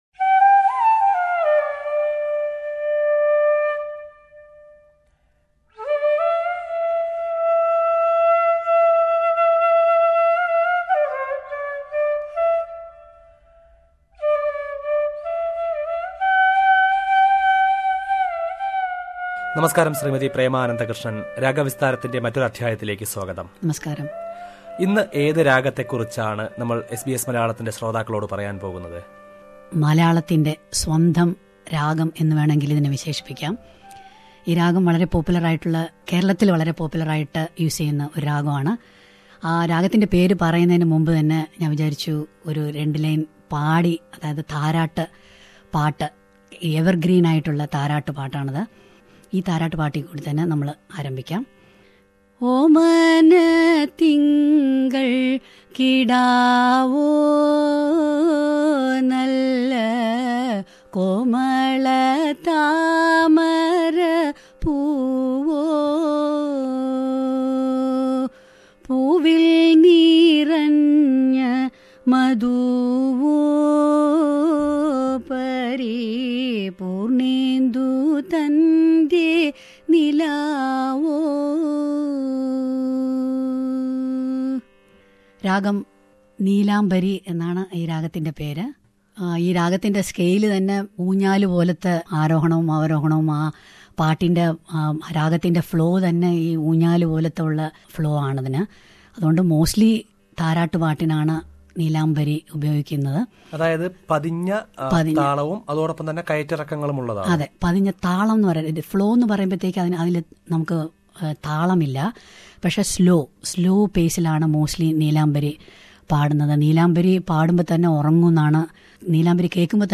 Listen to a nostalgic raga this time...
in SBS Studio